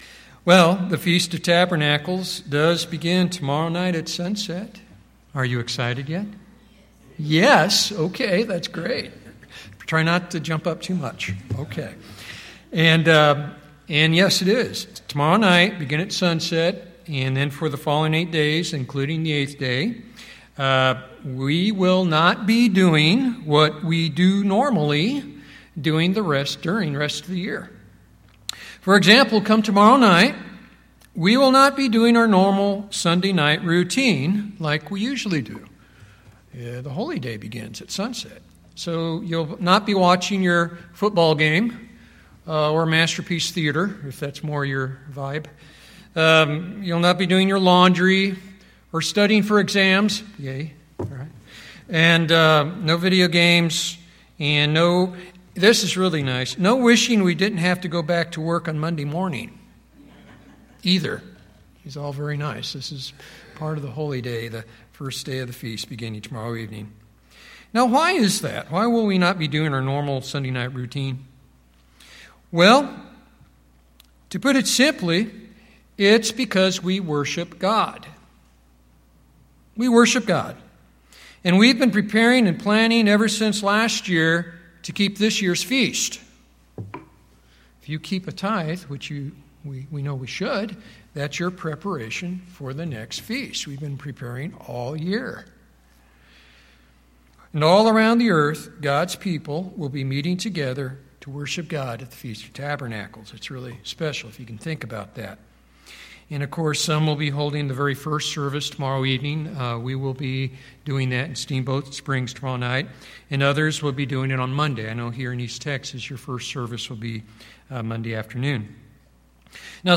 Those who keep the Feast of Tabernacles do so to worship God by obeying Him, reverencing Him and serving Him. Learn, through this sermon, how true worshippers of God keep the Feast -past, present, and future.